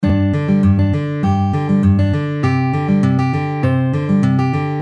EXAMPLE 2 Fingerpicking